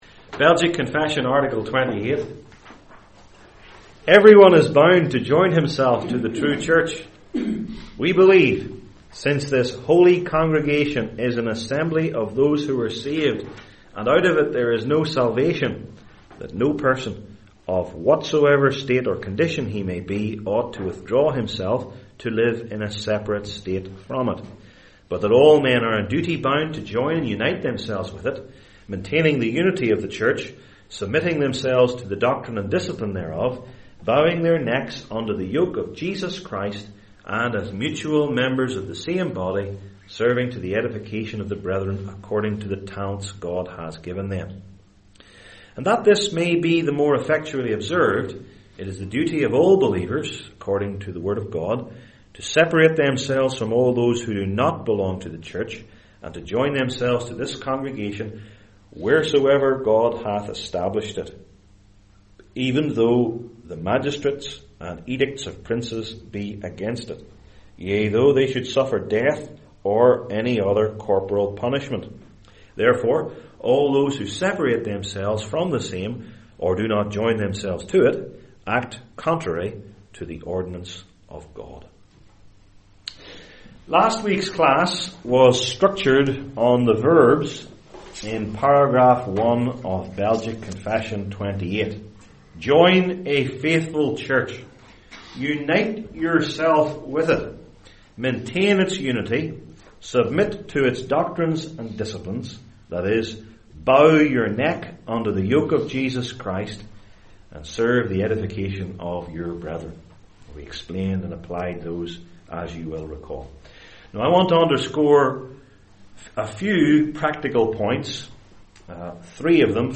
Service Type: Belgic Confession Classes Article 28: Every One is Bound to Join Himself to the True Church.